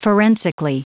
Prononciation du mot forensically en anglais (fichier audio)
Prononciation du mot : forensically